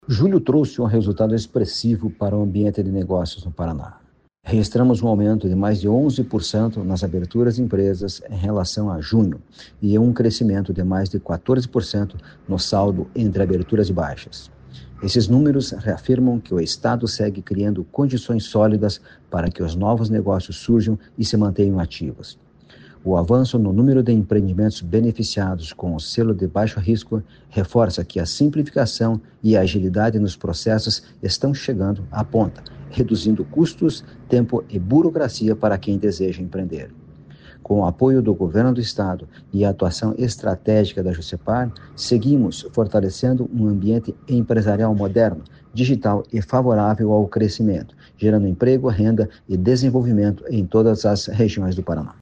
Sonora do presidente da Jucepar, Marcos Rigoni, sobre o aumento de 15,1% na abertura de negócios em 2025